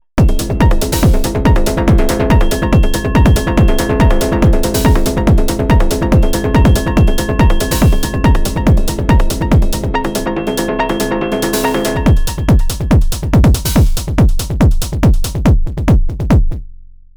Demonstration of the Rumblekick, and the improved 909 closed hat
(solo drums at the end)